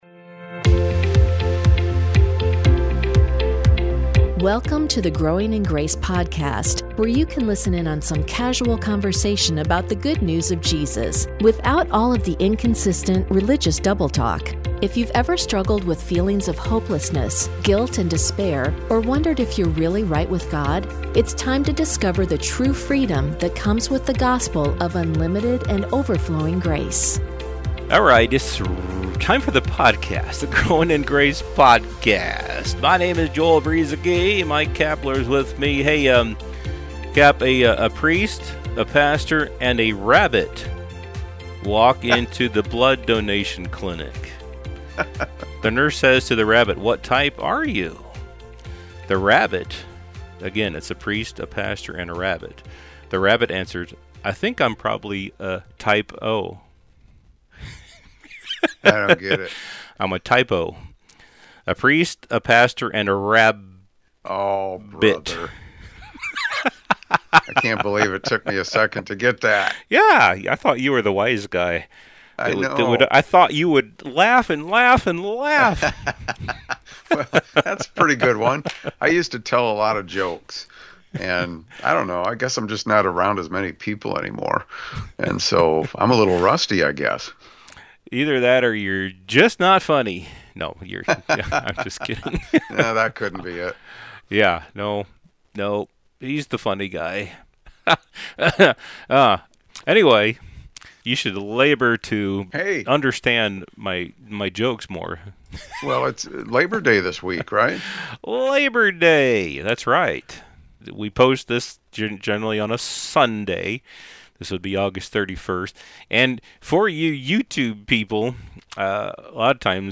as they casually chat about growing in understanding the gospel and living in the freedom that comes through Jesus Christ.